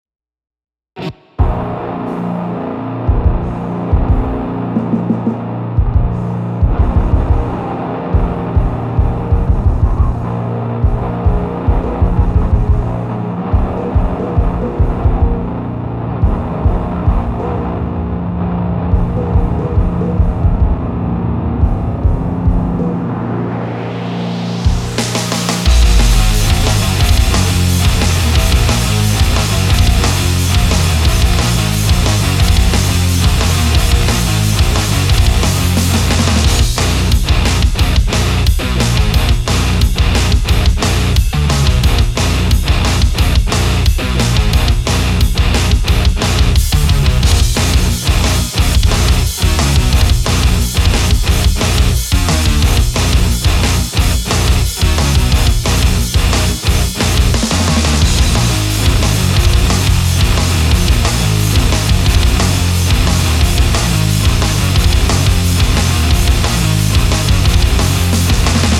my first metalcore attempt
Cutting to the chase: I recently started recording a kind of metalcore stuff.
I'm a newbie and I don't have a decent recording equipement but I think it sounds well. I plan to write and record vocals and later finish the whole track.